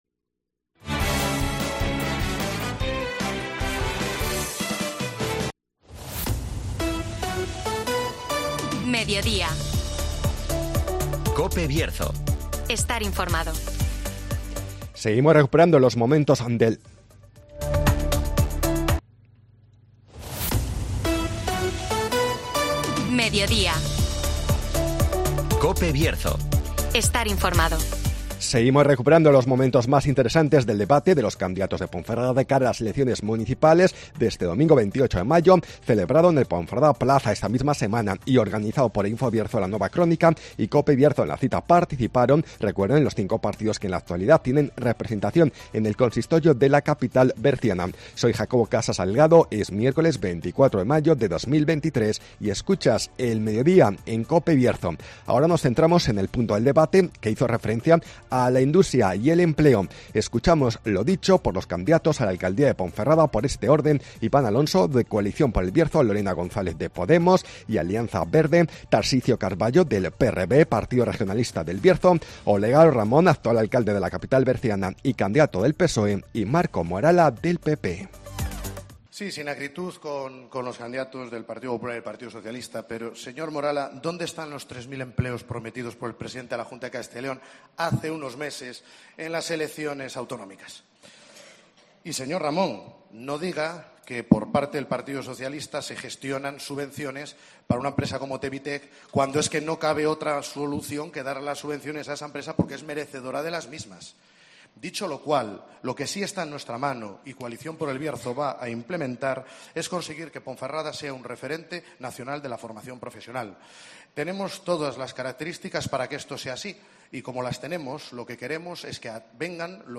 Debate con los candidatos de Ponferrada a las elecciones municipales del 28 de mayo (Parte 4)